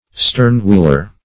Search Result for " stern-wheeler" : The Collaborative International Dictionary of English v.0.48: Stern-wheeler \Stern"-wheel`er\, n. A steamboat having a stern wheel instead of side wheels.